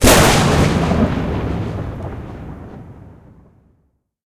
slapstickThunder.ogg